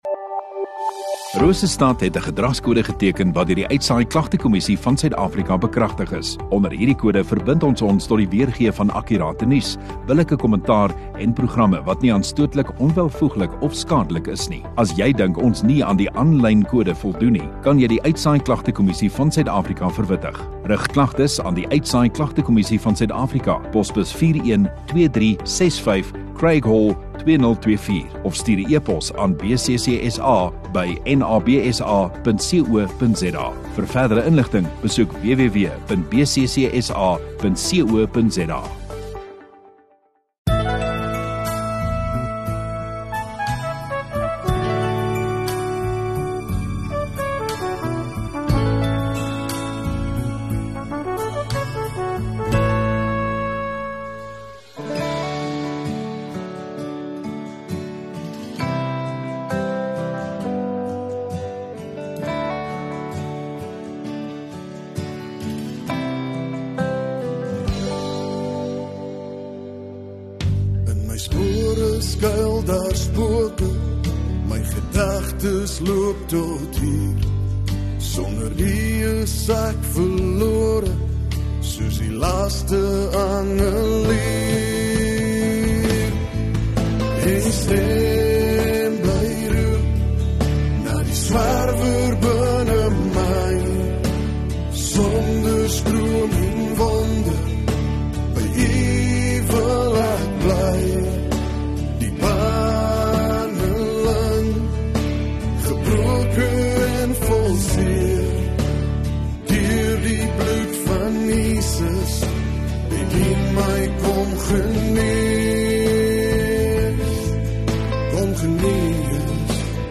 18 May Sondagaand Erediens